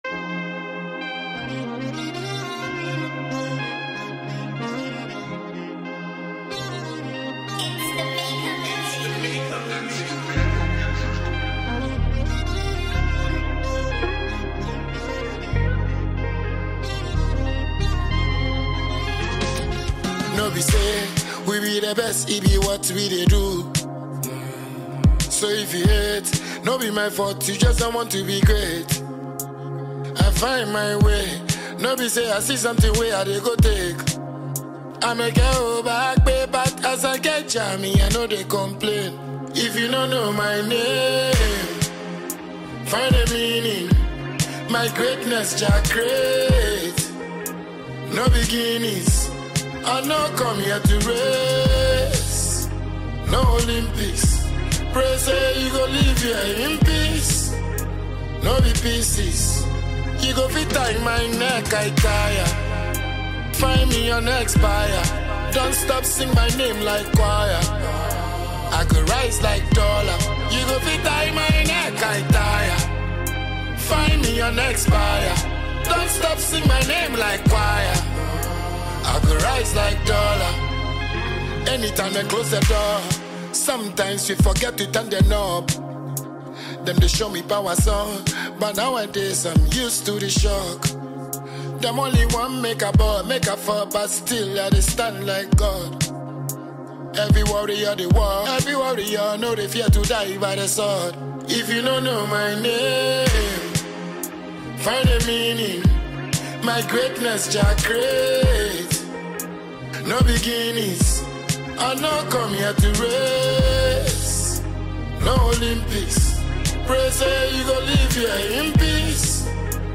Multiple award-winning Ghanaian dancehall musician